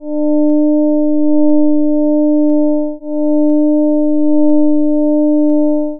虚部の音声wavファイル(L設定)